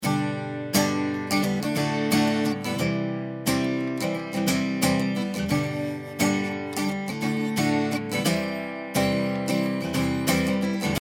スロープが12dB/octだとこんな感じです。
ローを切ってしまったので、少し軽めの音になりましたね。